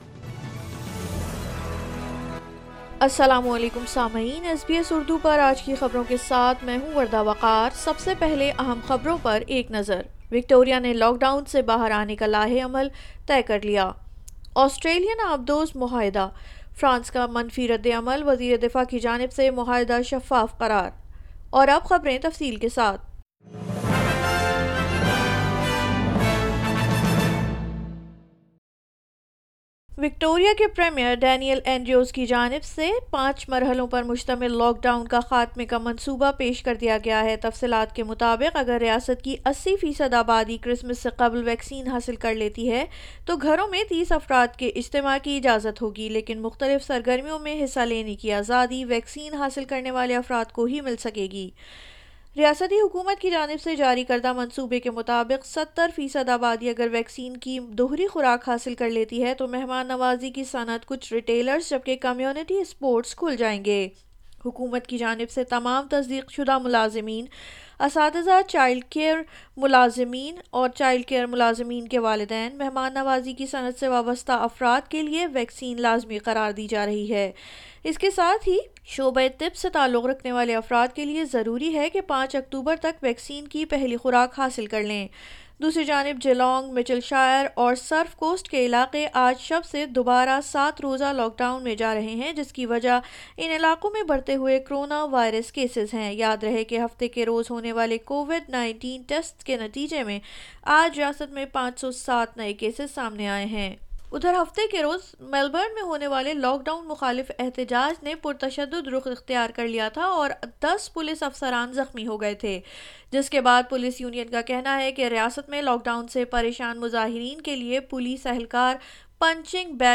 Urdu News 19 Sep 2021